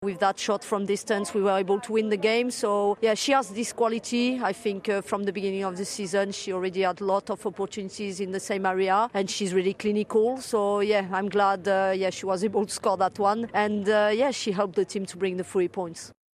Boss Sonia Bompastor tells Sky Sports News, the England midfielder’s contribution was key.